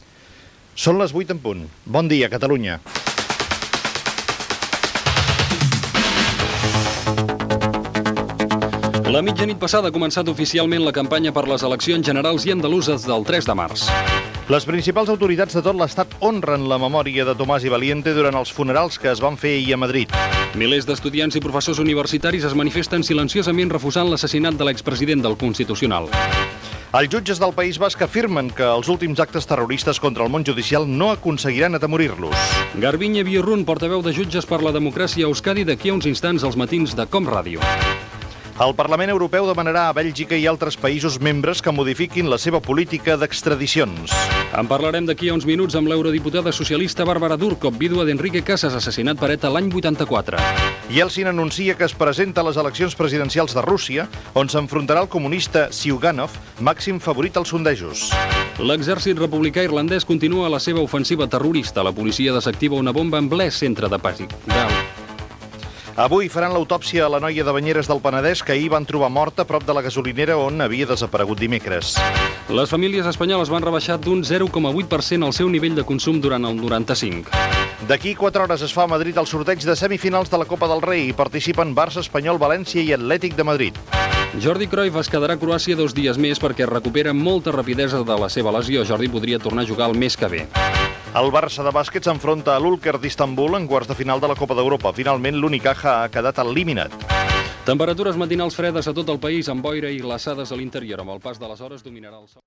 Hora i resum de titulars informatius del dia
Info-entreteniment